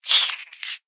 Minecraft Version Minecraft Version snapshot Latest Release | Latest Snapshot snapshot / assets / minecraft / sounds / mob / silverfish / kill.ogg Compare With Compare With Latest Release | Latest Snapshot
kill.ogg